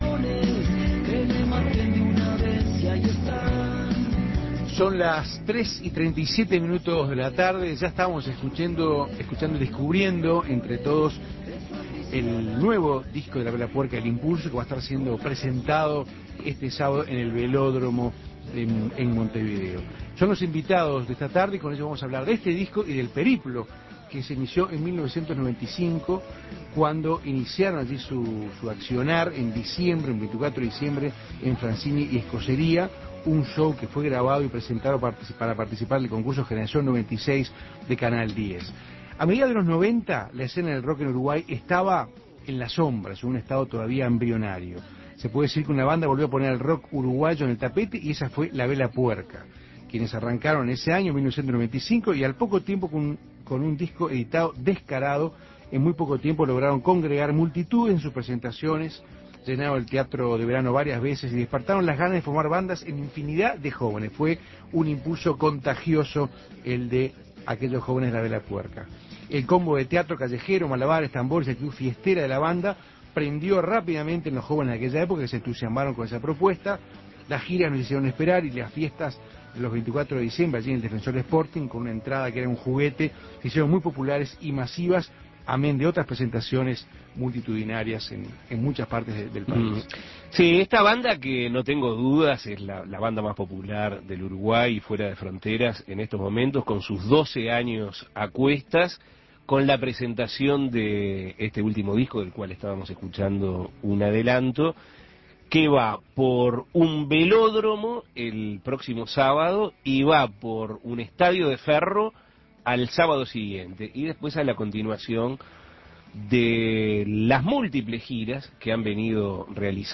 El 1º de setiembre a las 20 horas, en el Velódromo Municipal, La Vela Puerca presentará "El Impulso", su último disco. Sebastián Teysera, Sebastián Cebreiro y Santiago Butler, integrantes de la banda, estuvieron en Asuntos Pendientes contando sobre este nuevo desafío.